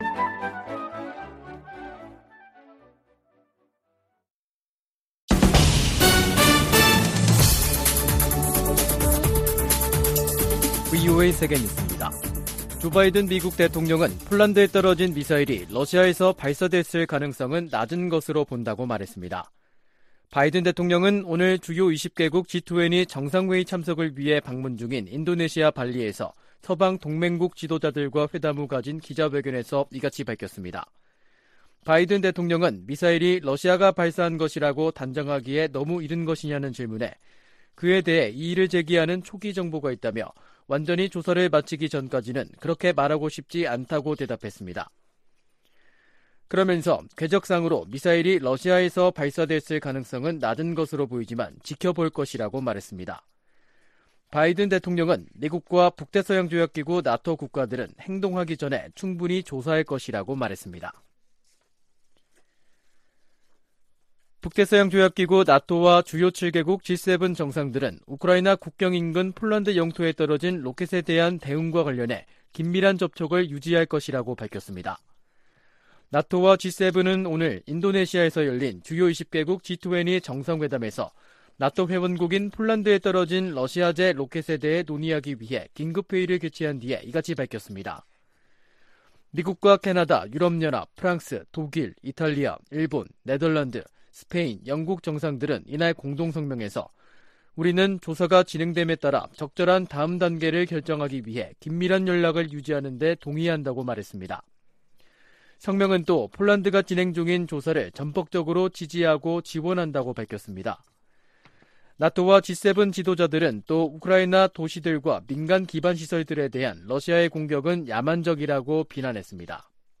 VOA 한국어 간판 뉴스 프로그램 '뉴스 투데이', 2022년 11월 16일 3부 방송입니다. 도널드 트럼프 전 미국 대통령이 2024년 대통령 선거에 다시 출마할 것이라고 공식 발표했습니다. 한국과 중국 두 나라 정상이 15일 열린 회담에서 북한 문제에 대해 논의했지만 해법을 놓고는 기존 시각차를 확인했을 뿐이라는 평가가 나오고 있습니다.